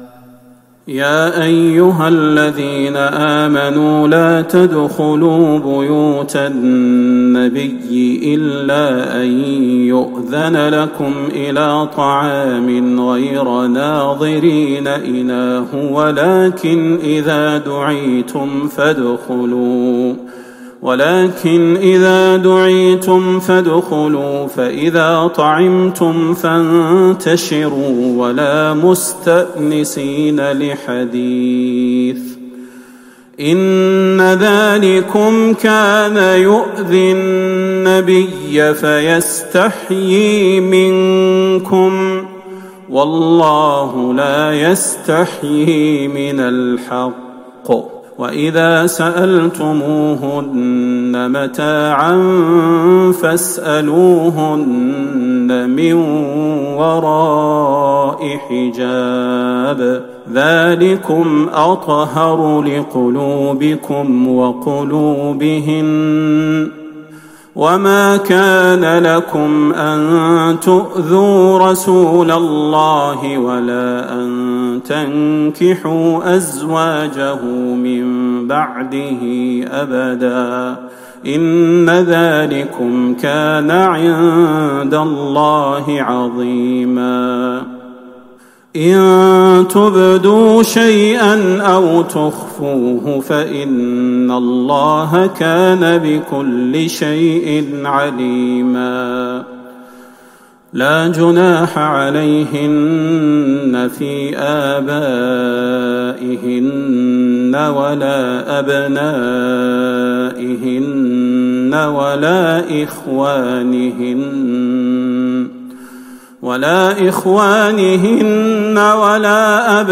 تهجد ٢٤ رمضان ١٤٤١هـ من سورة الأحزاب { ٥٣-٧٣ } وسبأ { ١-٢٣ } > تراويح الحرم النبوي عام 1441 🕌 > التراويح - تلاوات الحرمين